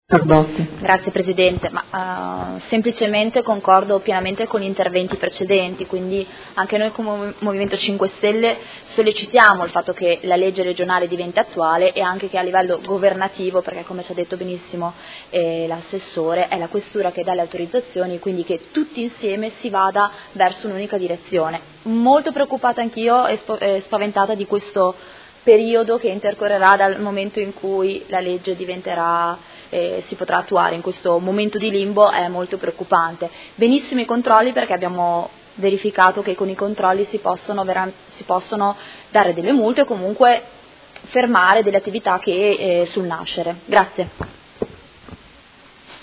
Seduta del 27/04/2017 Dibattito. Interrogazione dei Consiglieri Baracchi e Poggi (PD) avente per oggetto: Apertura Sala Slot c/o piano terra Palazzo Europa.